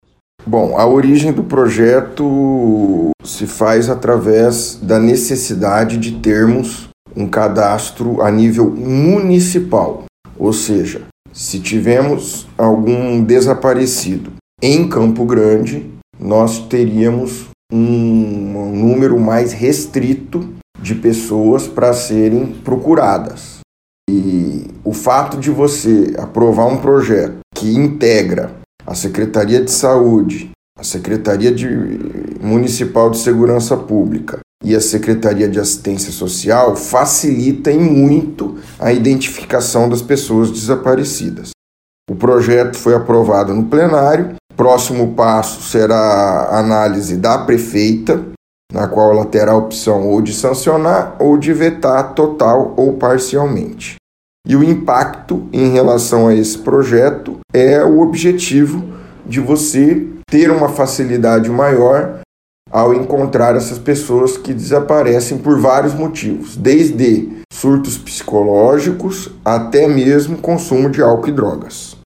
Audio-vereador-Otavio-Trad.mp3